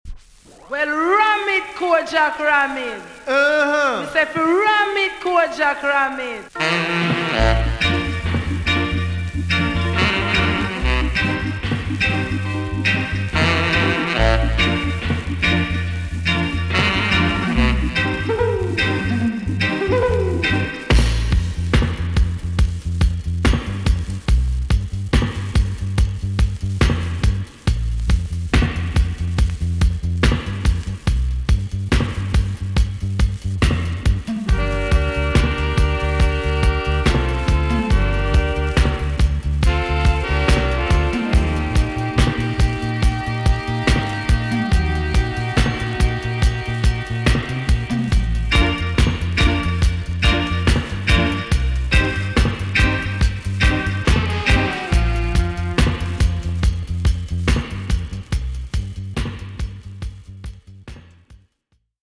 B.SIDE Version
EX-~VG+ 少し軽いヒスノイズがありますが良好です。